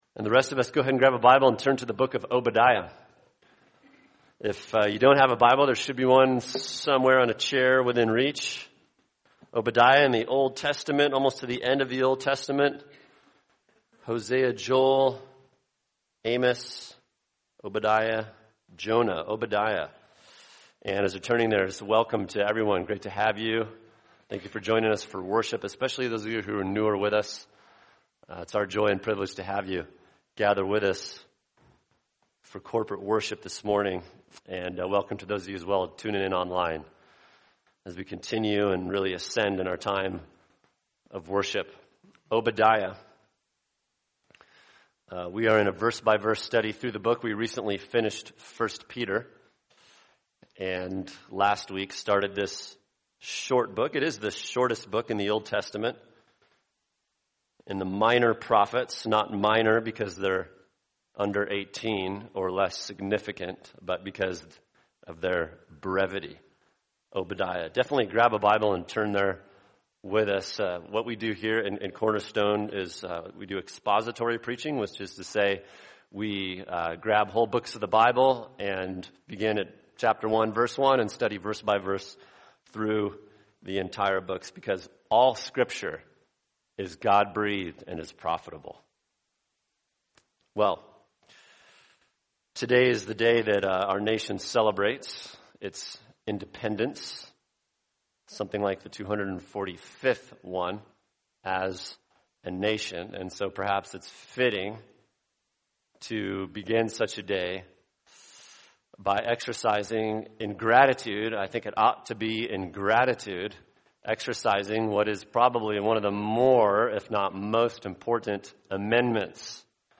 [sermon] Obadiah 1:3-9 The Perils of Our Pride | Cornerstone Church - Jackson Hole